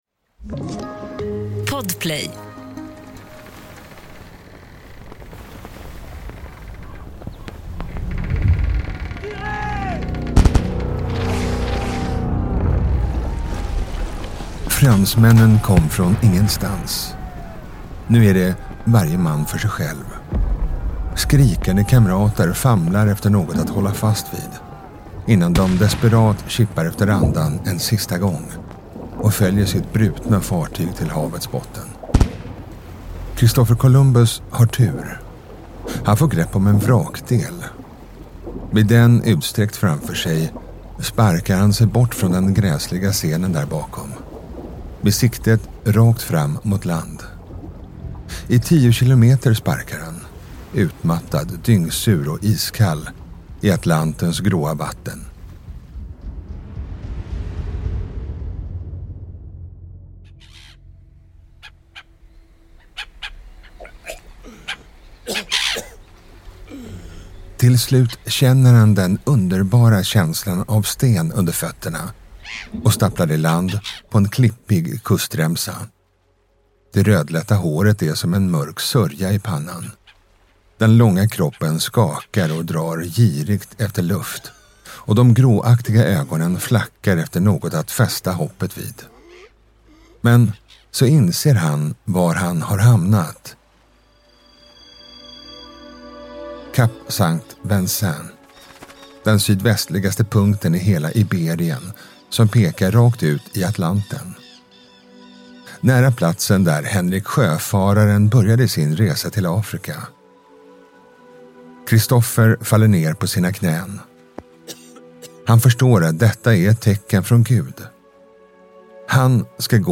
Berättarröst